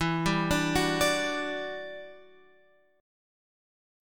E Minor 9th